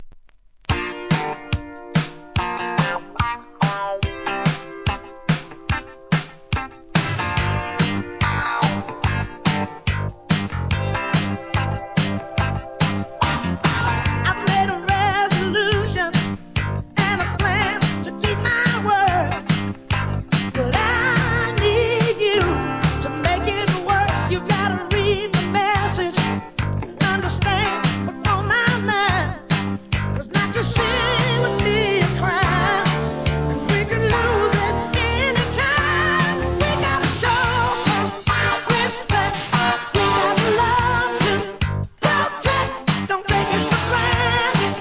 ¥ 550 税込 関連カテゴリ SOUL/FUNK/etc...